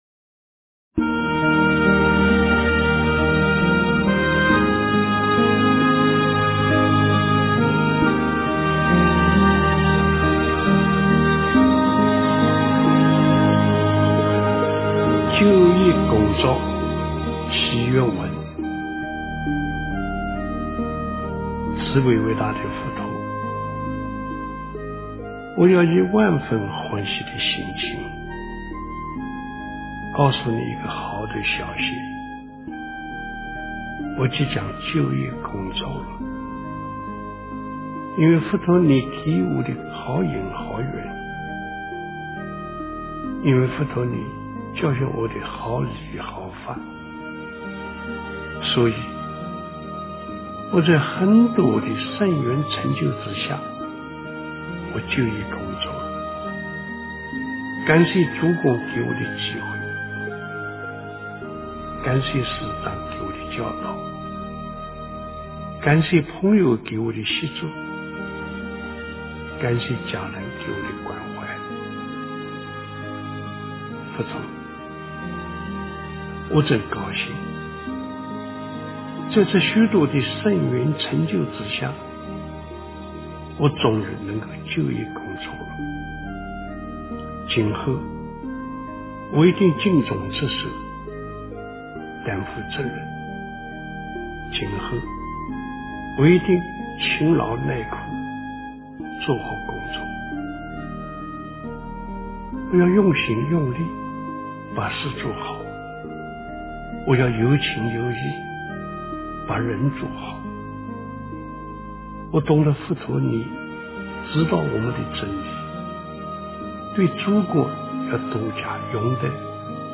就业工作祈愿文 诵经 就业工作祈愿文--星云大师 点我： 标签: 佛音 诵经 佛教音乐 返回列表 上一篇： 消灾吉祥神咒 下一篇： 阿妈佛心上的一朵莲 相关文章 大雄宝殿--未知 大雄宝殿--未知...